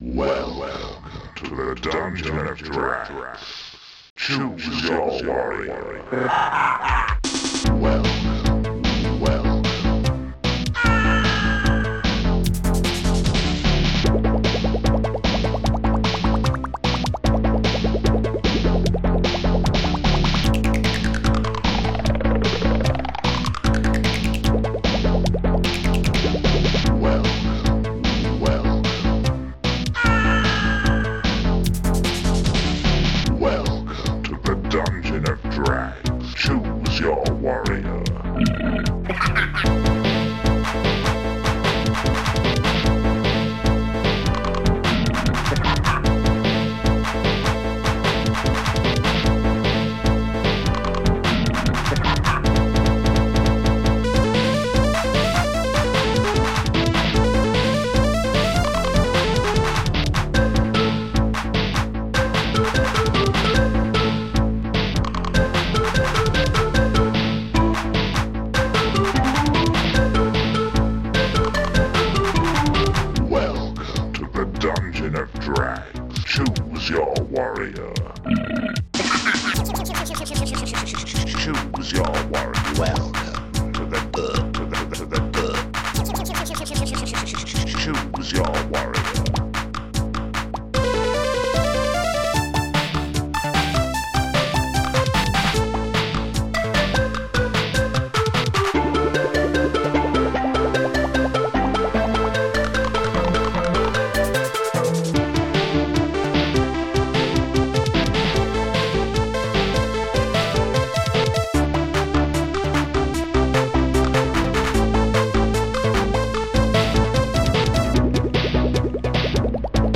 Protracker and family
st-12:dirtylaugh
st-14:funbass2
st-14:fingerclap